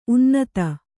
♪ unnata